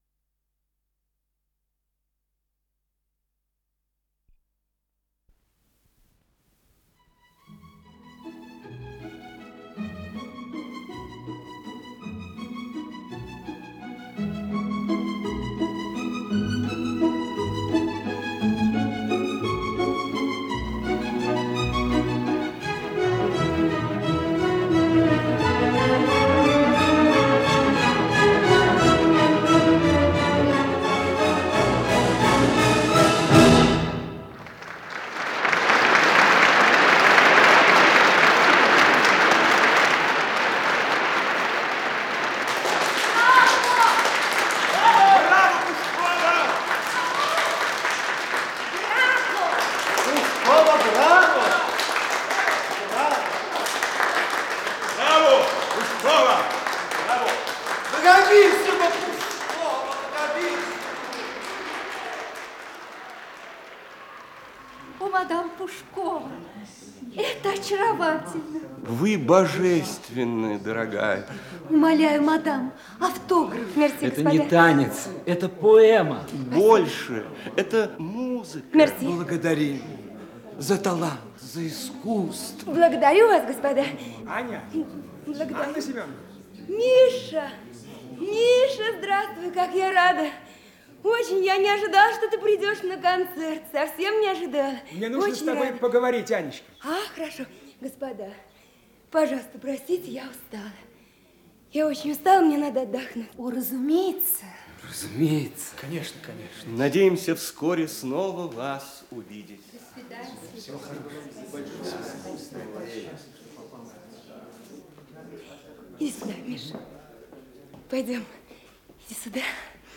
Исполнитель: Артисты московских театров
Радиоспектакль